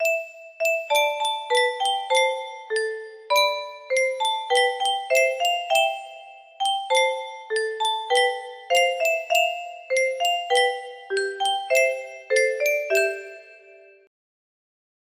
vv a ojo 15 music box melody